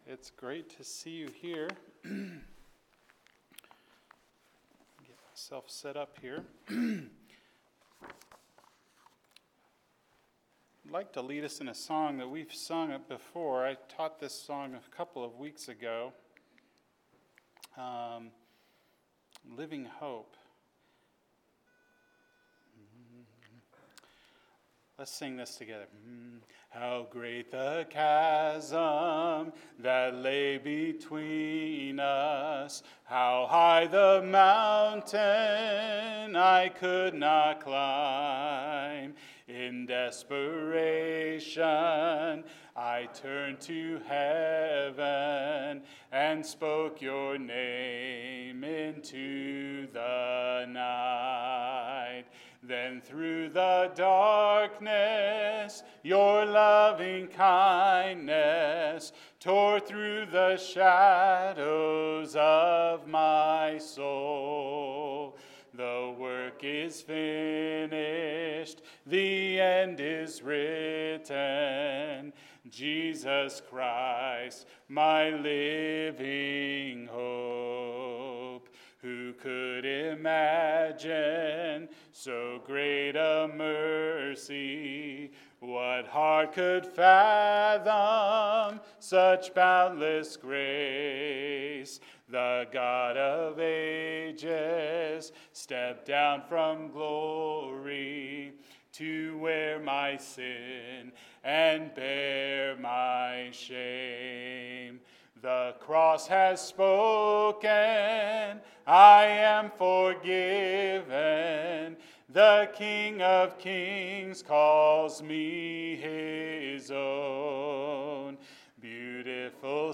Seven — Need – Sermon